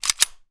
gun_pistol_cock_01.wav